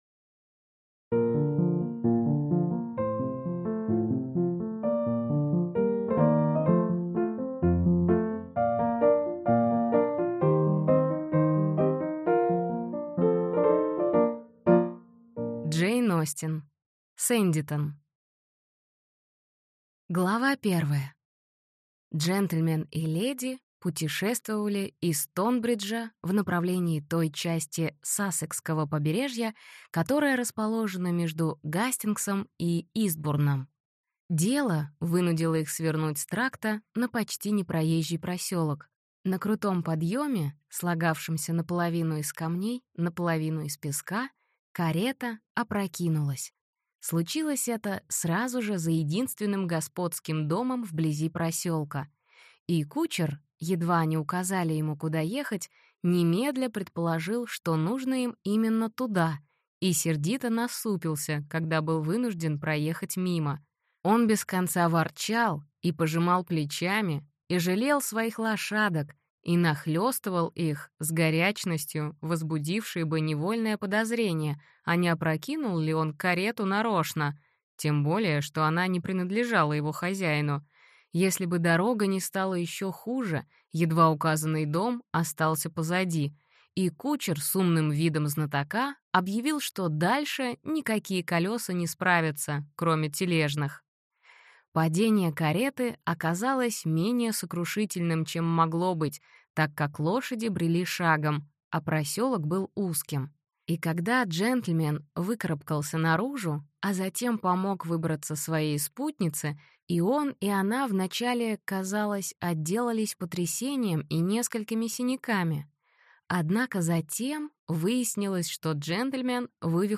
Аудиокнига Сэндитон | Библиотека аудиокниг